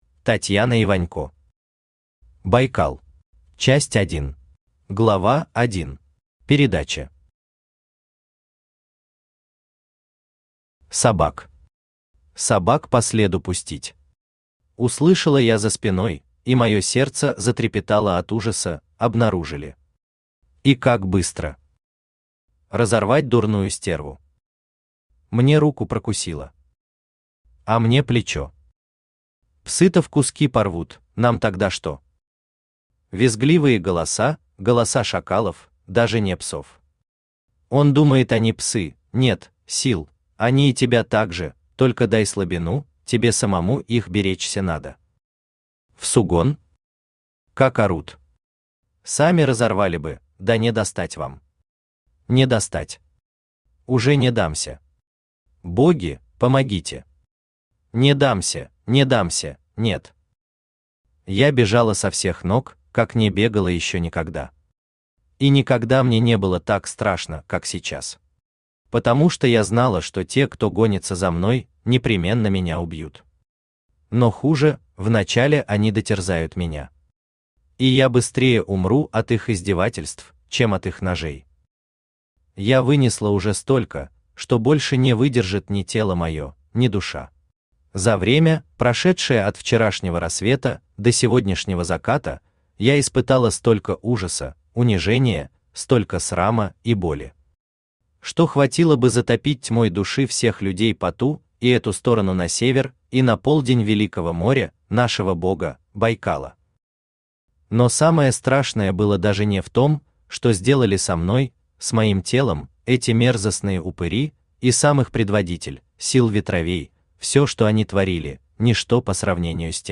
Aудиокнига Байкал Автор Татьяна Вячеславовна Иванько Читает аудиокнигу Авточтец ЛитРес.